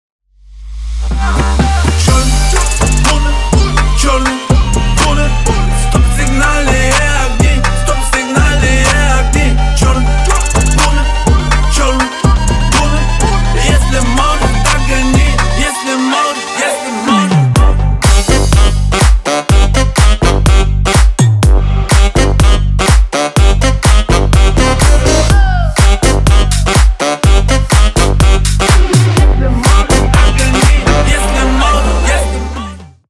бесплатный рингтон в виде самого яркого фрагмента из песни
Ремикс # Танцевальные